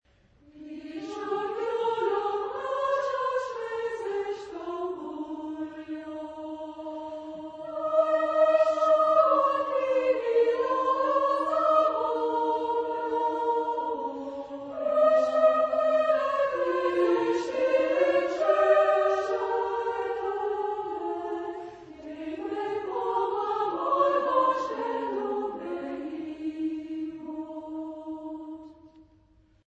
Epoque: 20th century
Type of Choir: SA  (2 women voices )